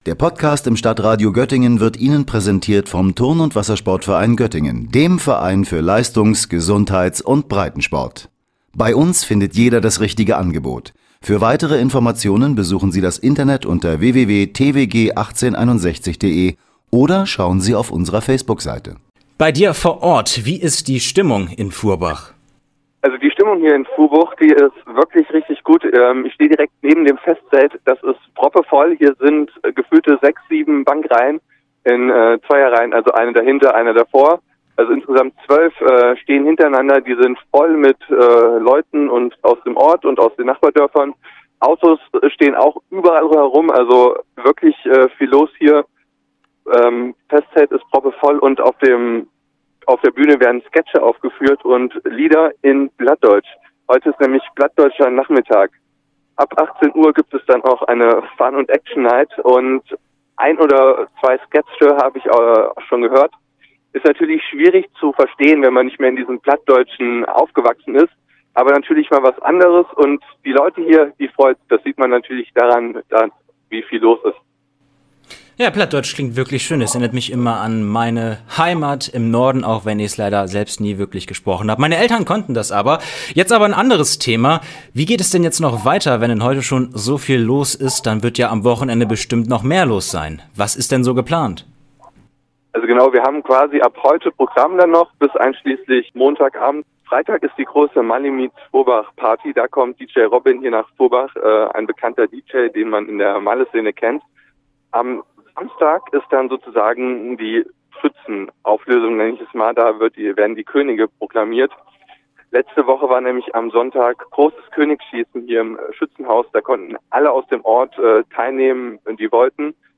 Live_Furhbach_ONLINE-playout.mp3